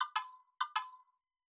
Knock Notification 3.wav